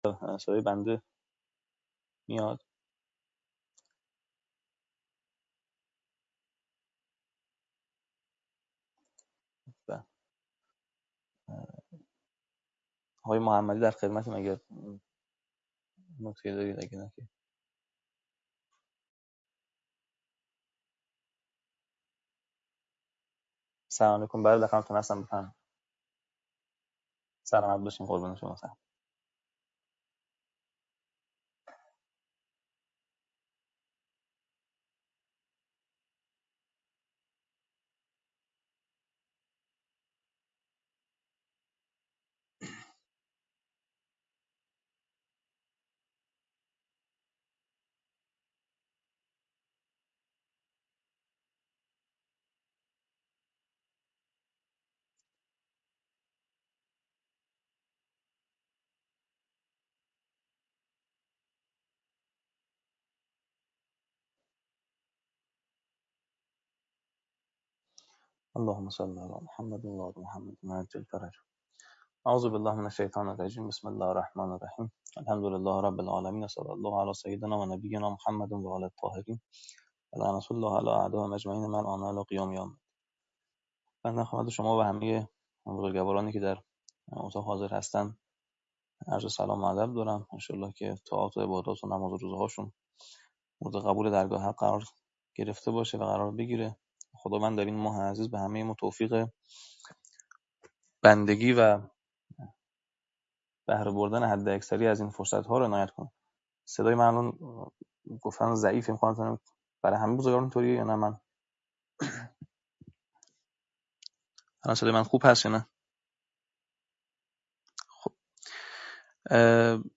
انسان شناسی و جهان شناسی - جلسه-پرسش-و-پاسخ